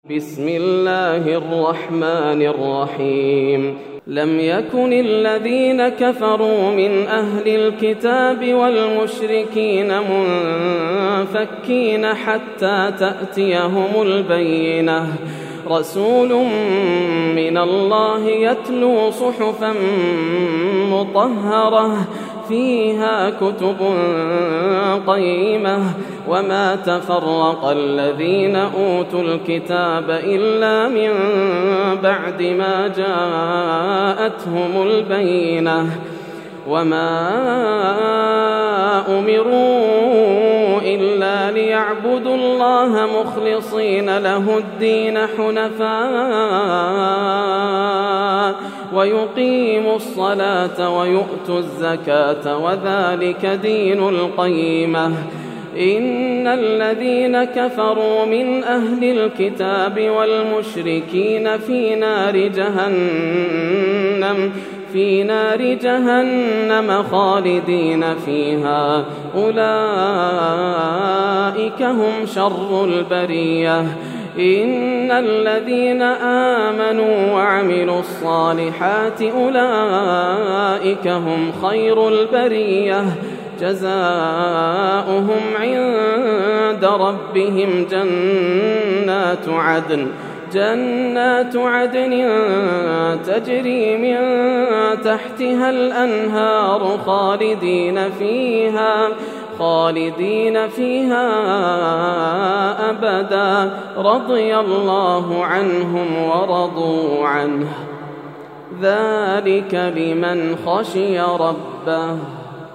سورة البينة > السور المكتملة > رمضان 1431هـ > التراويح - تلاوات ياسر الدوسري